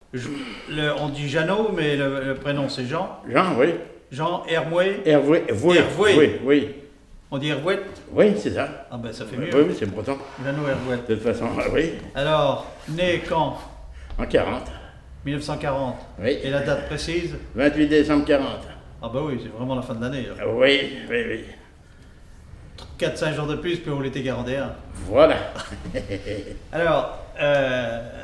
Témoignage sur la musique et des airs issus du Nouc'h
Catégorie Témoignage